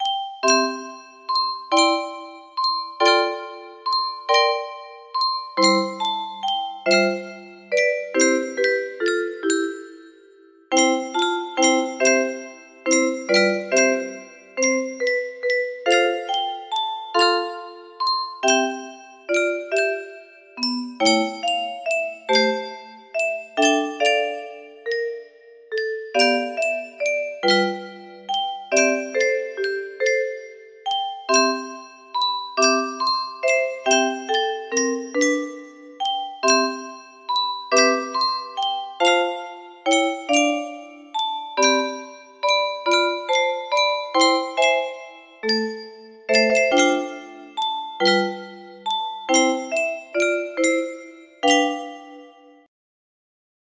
His eyes is on the sparrow music box melody
Yay! It looks like this melody can be played offline on a 30 note paper strip music box!